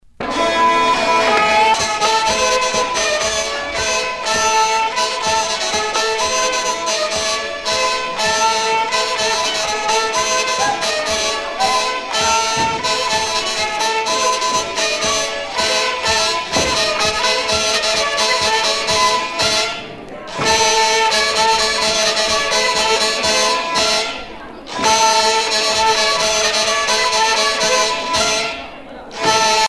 vielle traditionnels en Bretagne
Pièce musicale éditée